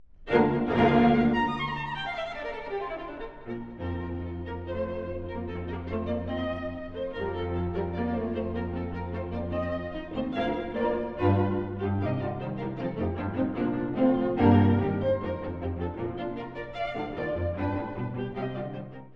↑古い録音のため聴きづらいかもしれません！（以下同様）
華やかな終楽章。
疾走感あふれる、快活なビバーチェです。